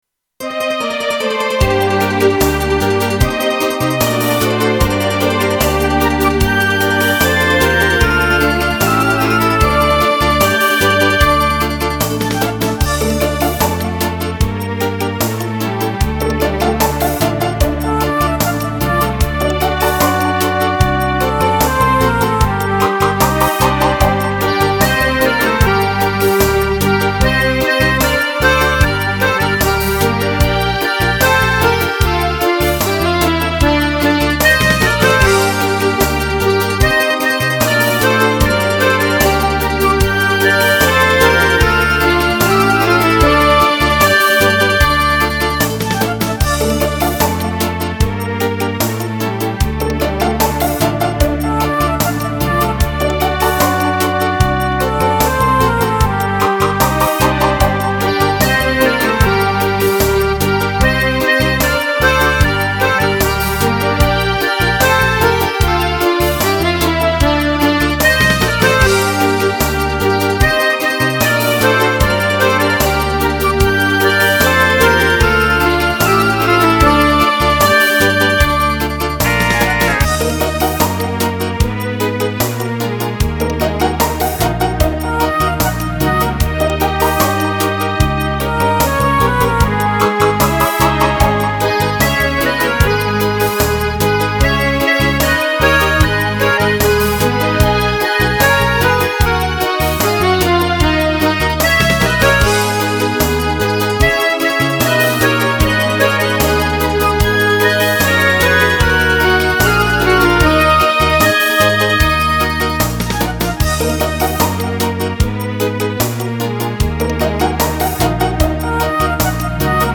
Застольные / Народные